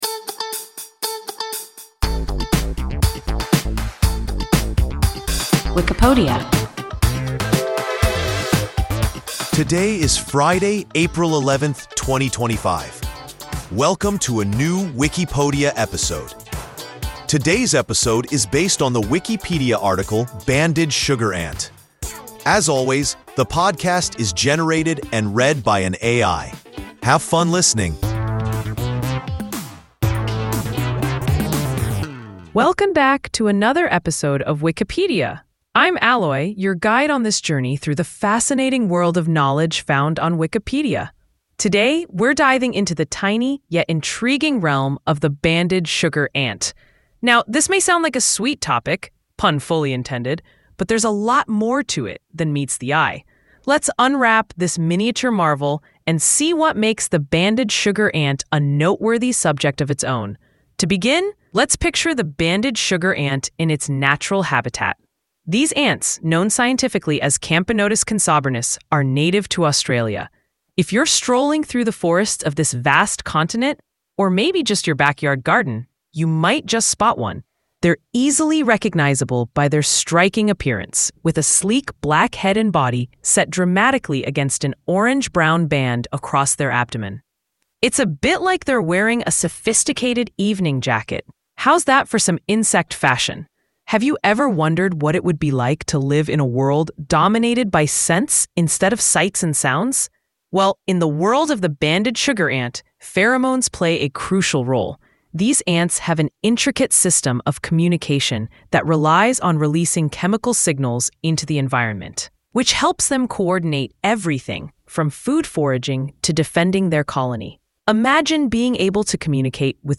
Banded sugar ant – WIKIPODIA – ein KI Podcast